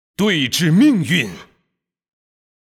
技能语音